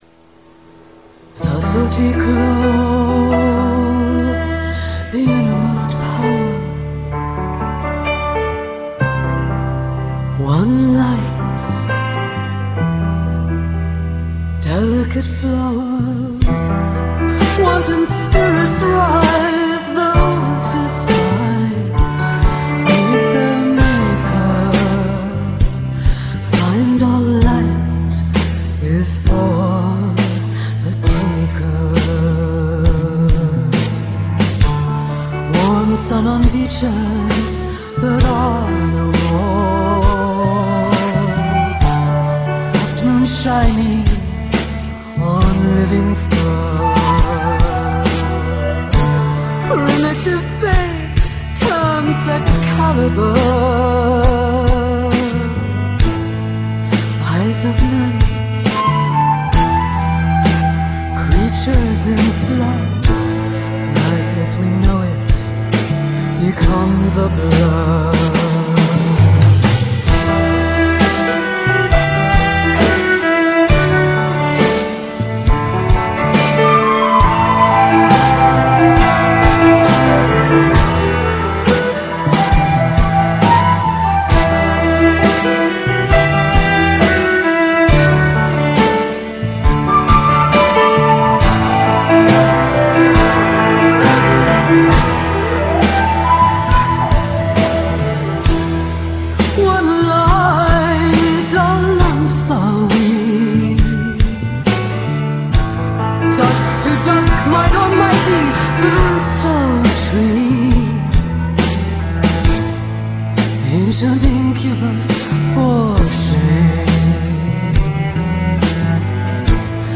Real Audio/mono